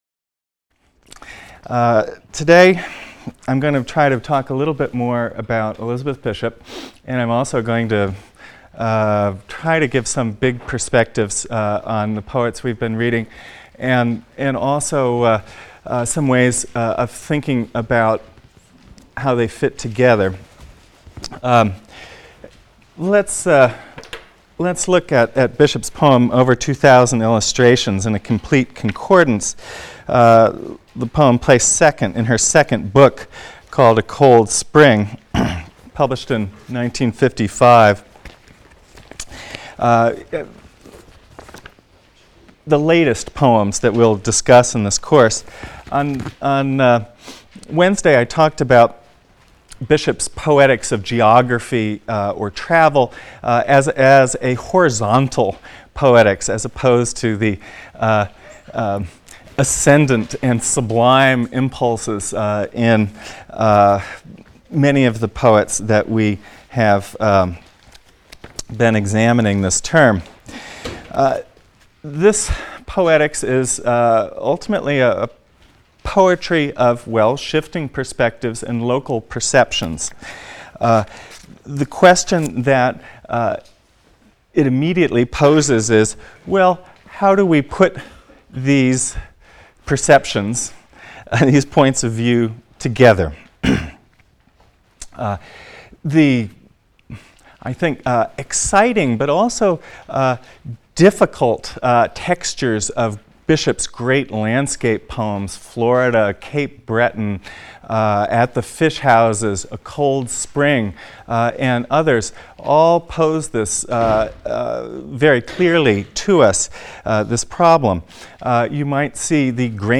ENGL 310 - Lecture 25 - Elizabeth Bishop (cont.)